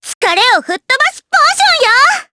May-Vox_Skill2_jp.wav